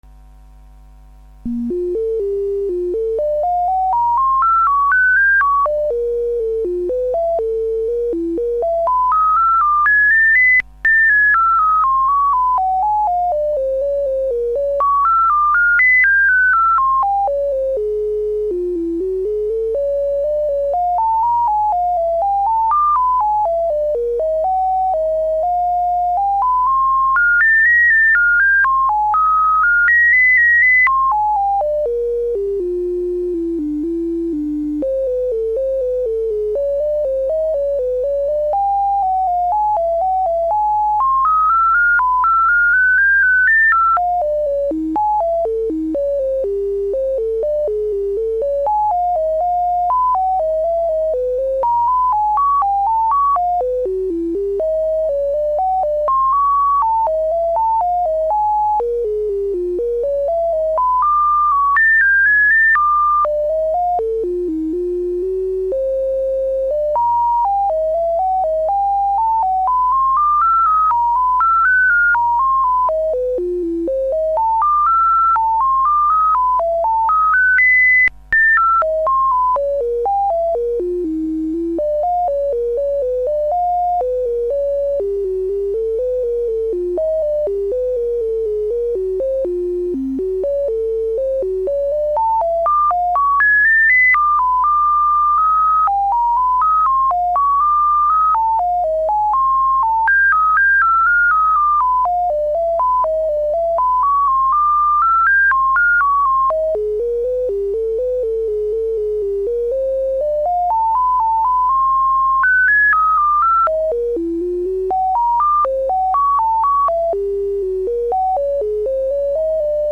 Терменнот, или терменвокс на микроконтроллере.